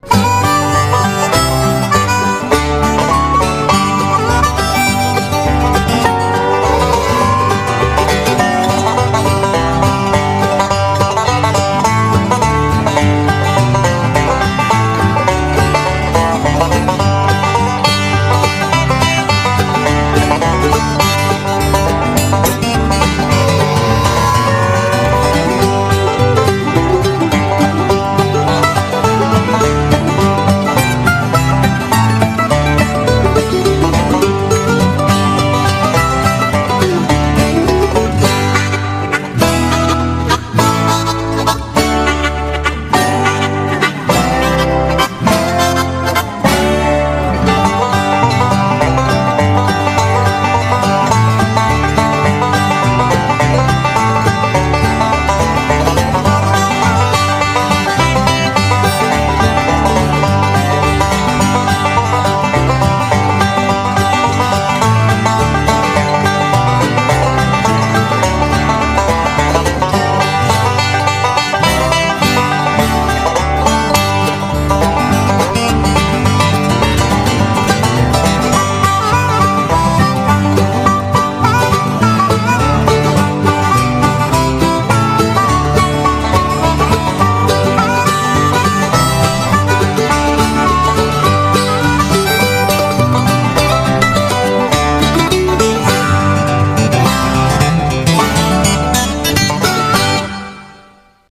BPM104
Since it is a live performance so it's not always on beat.